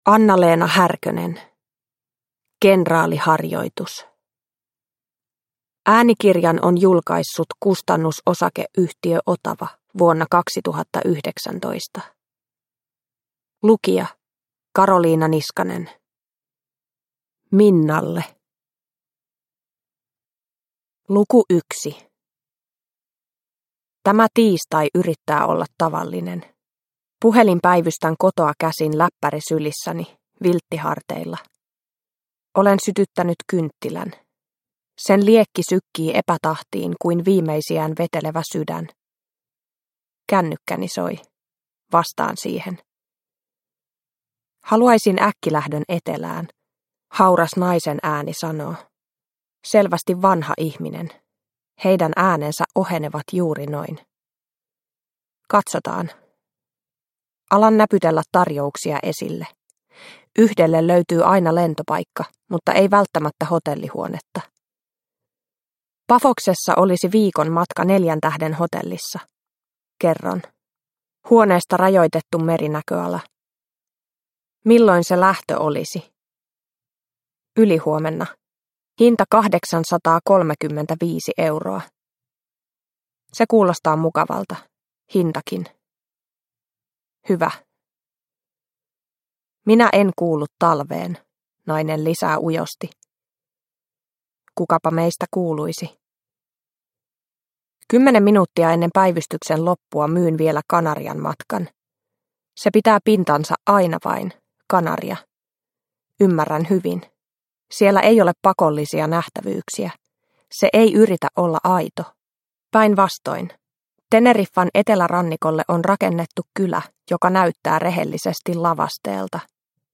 Kenraaliharjoitus – Ljudbok – Laddas ner